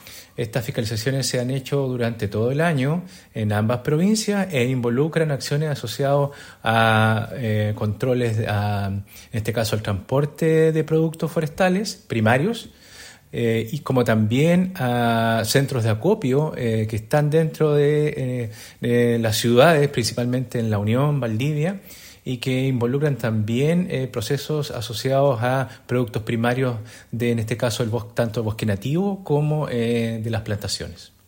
Arnoldo Shibar, director regional de CONAF Los Ríos.